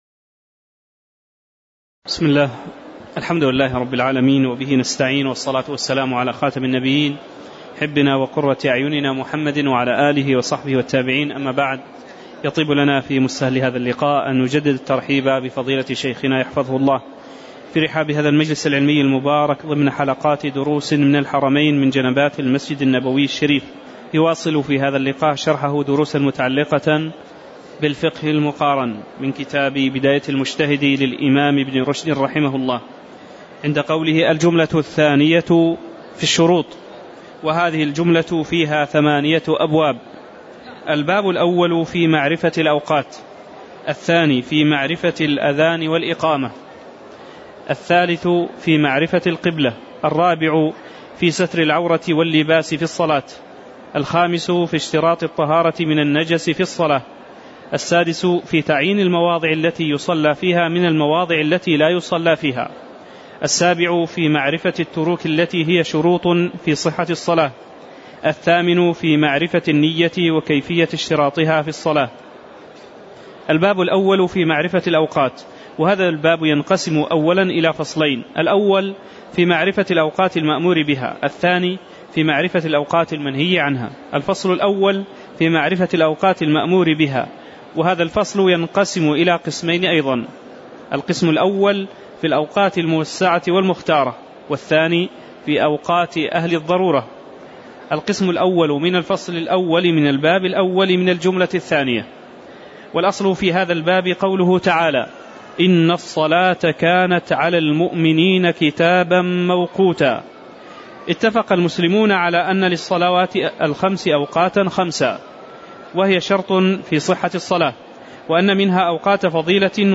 تاريخ النشر ٣٠ محرم ١٤٤١ هـ المكان: المسجد النبوي الشيخ